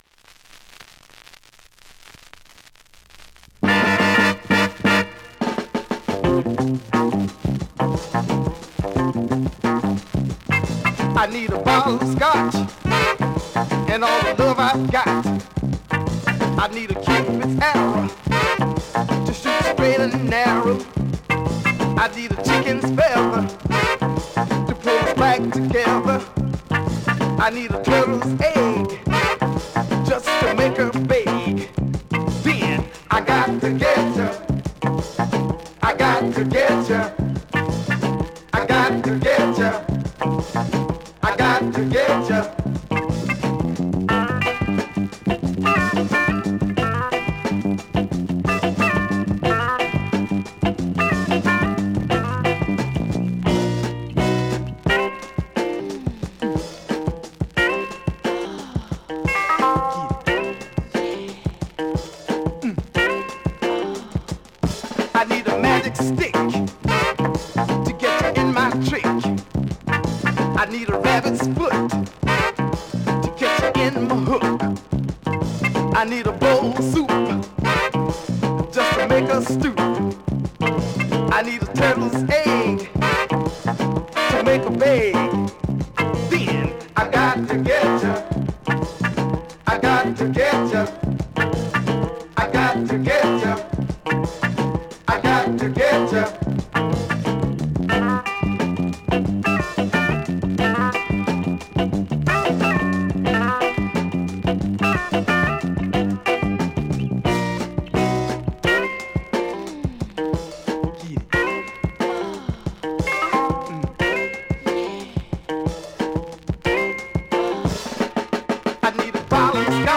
◆盤質両面/VGB面に軽いチリ程度◆